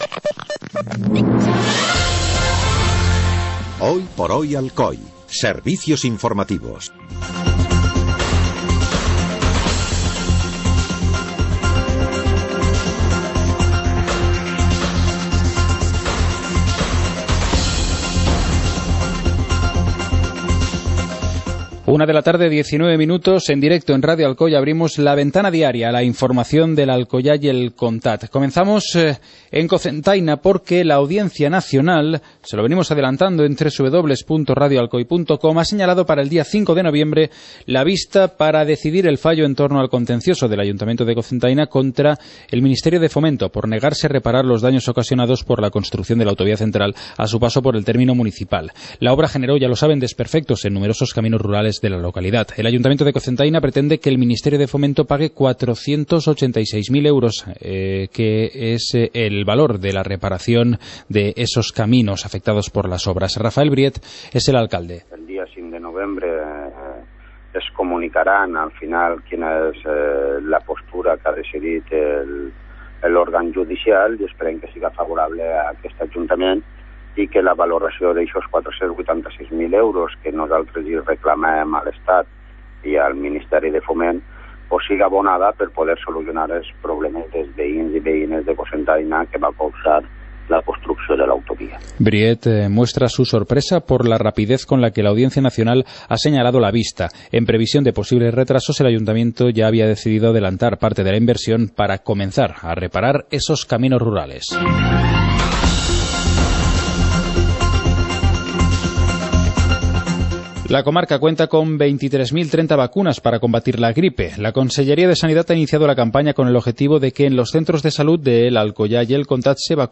Informativo comarcal - martes, 21 de octubre de 2014